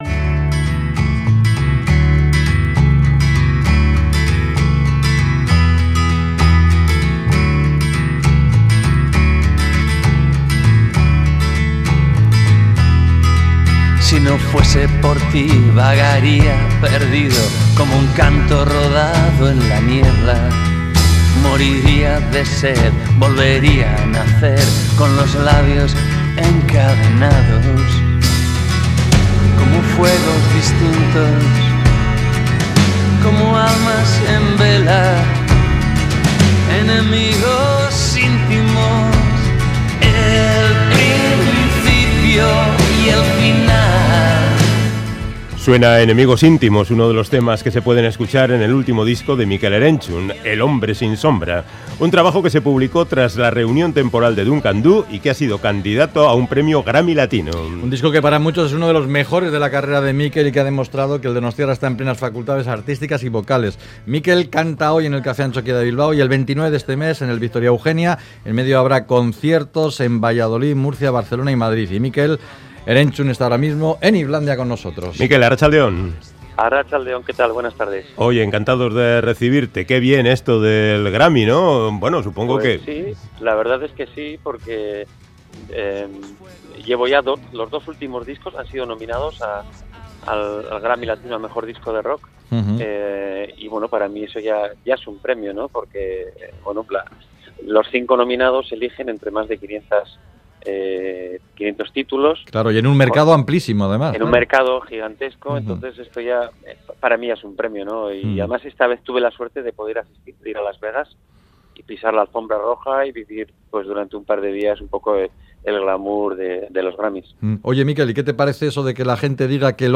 Audio: Charlamos con el compositor y cantante Mikel Erentxun antes de sus conciertos en Bilbao y Donostia, dentro de la gira de su último disco, El hombre sin sombra